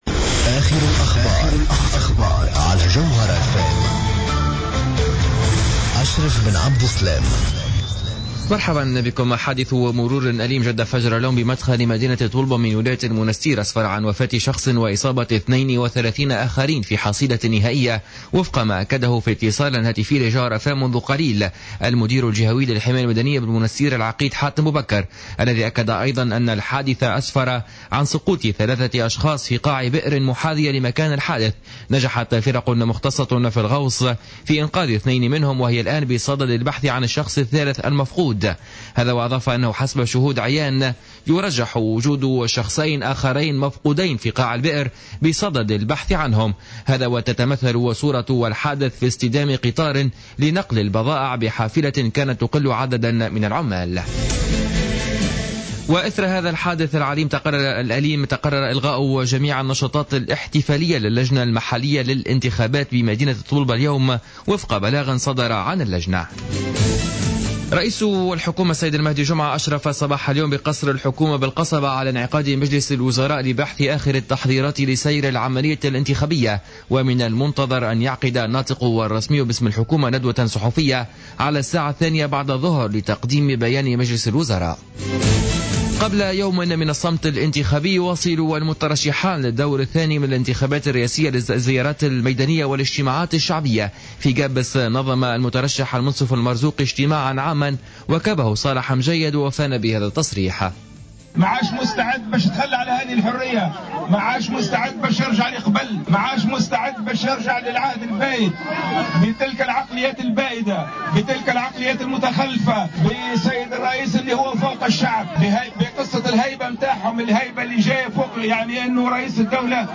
نشرة أخبار منتصف النهار ليوم الجمعة 19-12-14